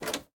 door_open.ogg